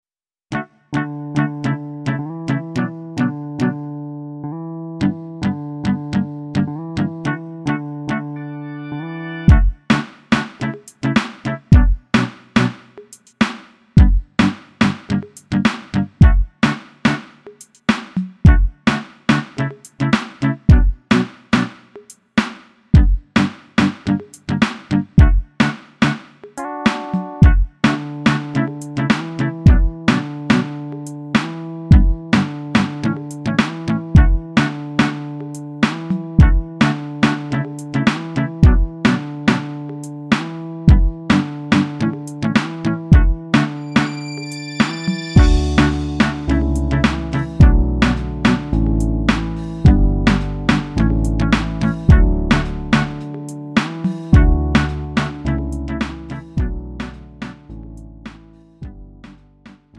음정 원키 3:31
장르 구분 Lite MR